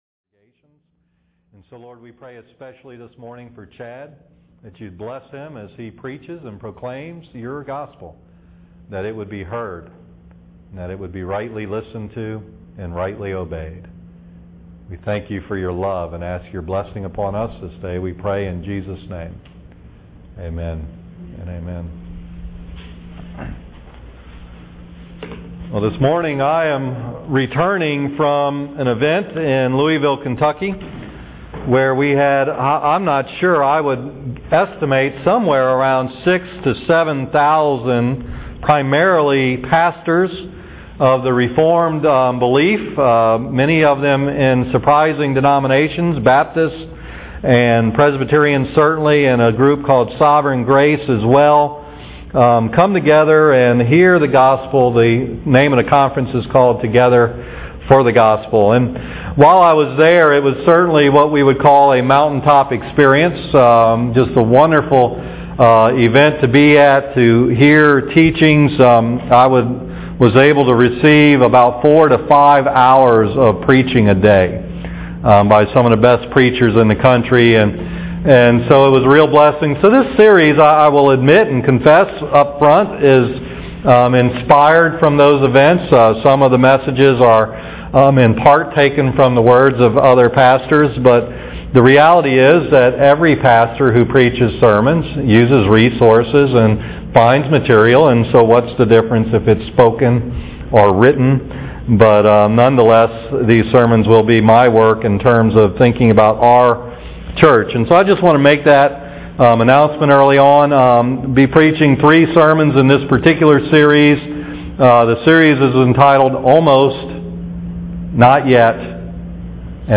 Sermon 1 in the series 1 Kings 18 “Almost”